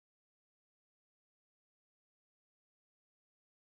PLAY Short Honky Tonk
honky-tonk-short_yeXESLu.mp3